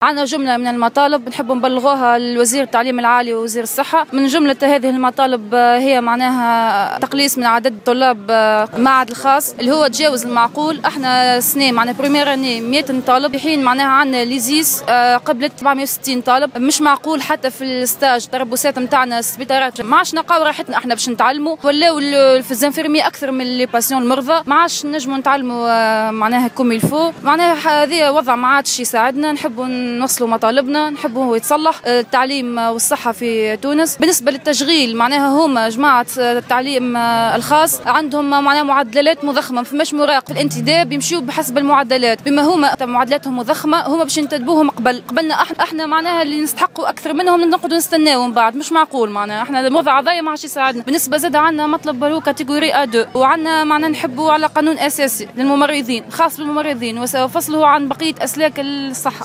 نفذ طلبة المعهد العالي لعلوم التمريض اليوم الخميس 12 فيفري 2015 وقفة احتجاجية أمام جامعة سوسة وذلك للمطالبة بتقليص عدد الطلبة في المعهد الخاص الذي تجاوز المعقول وفق ما أكدته إحدى المحتجات لجوهرة "اف ام" .